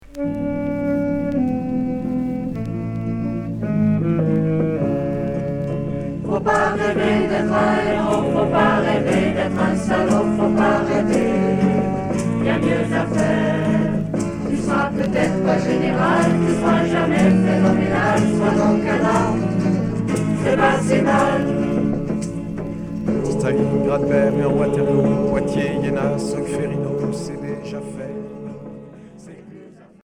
Folk religieux